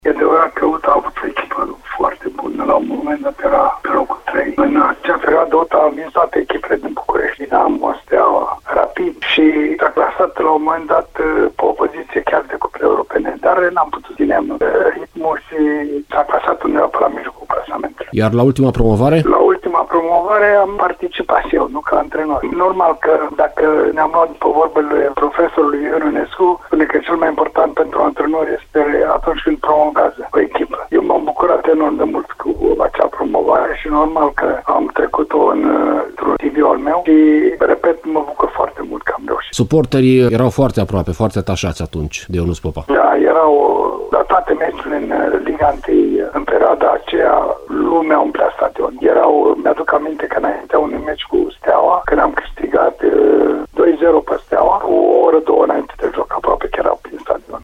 Pe site-ul nostru și în emisiunea Arena Radio de azi, după știrile orei 11, puteți asculta voci ale unor figuri legendare ale clubului de pe Mureș.